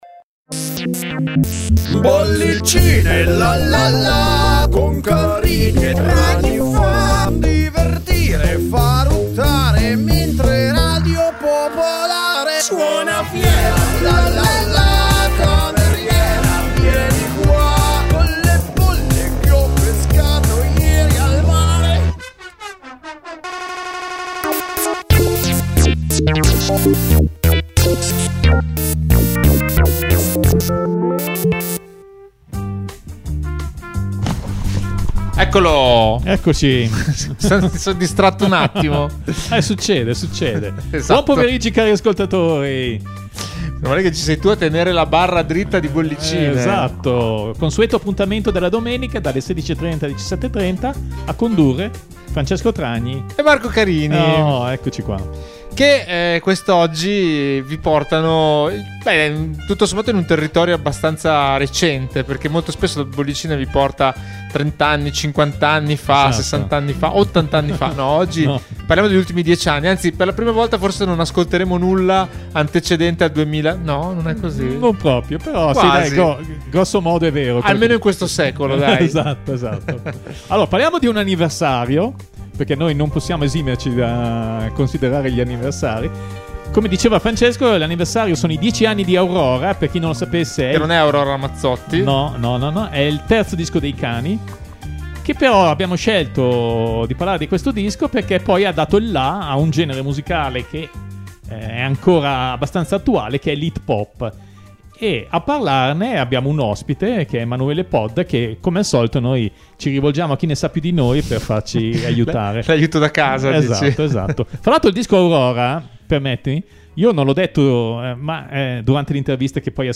Giunta alla stagione numero 17, Bollicine - ogni domenica, dalle 16.30 alle 17.30 - racconta la musica attraverso le sue storie e le voci dei suoi protagonisti: in ogni puntata un filo rosso a cui sono legate una decina di canzoni, con un occhio di riguardo per la musica italiana.